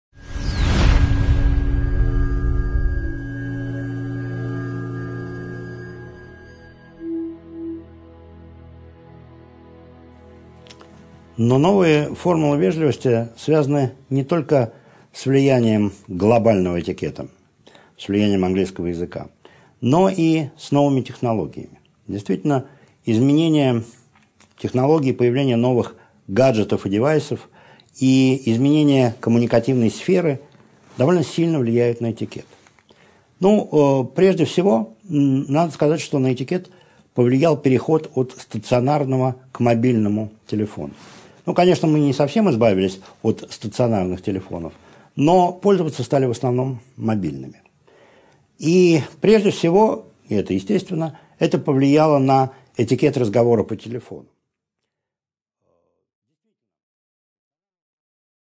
Аудиокнига 3.6 Новые формулы и новые технологии | Библиотека аудиокниг